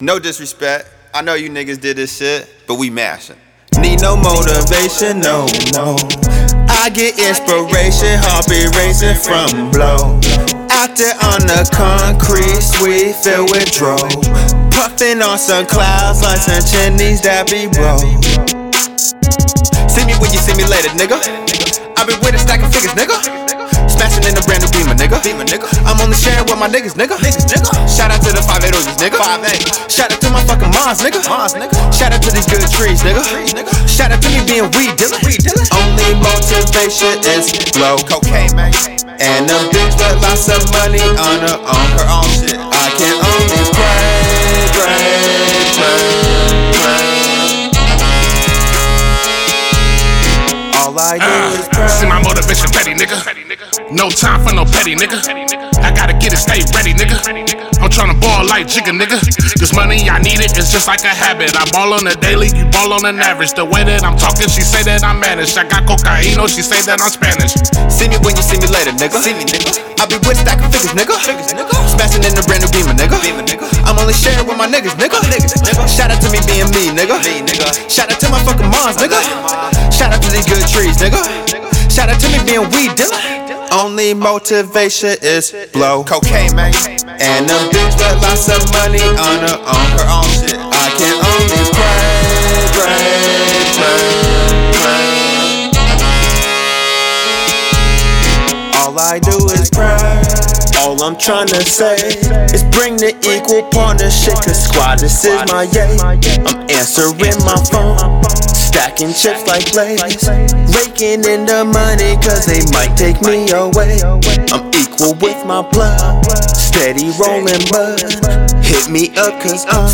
Hiphop
day to day grind motivational single